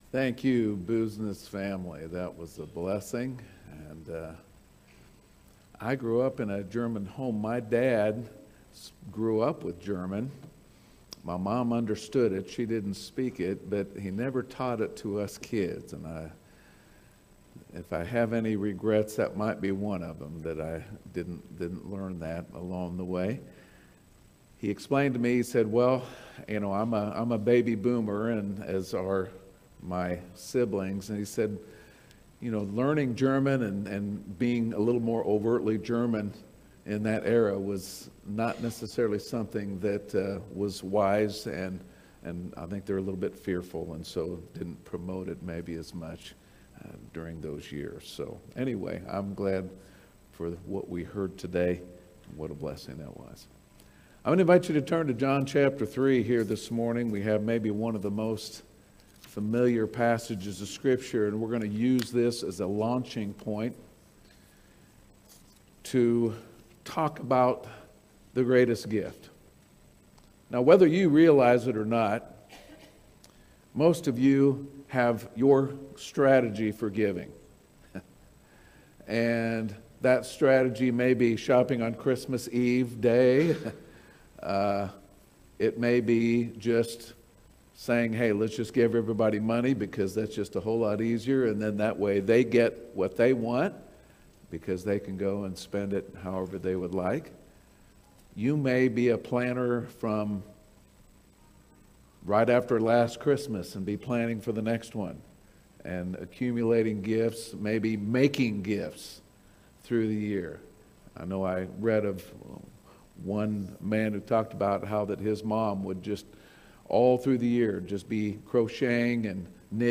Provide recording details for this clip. Our latest Sunday morning worship service Messages from God’s word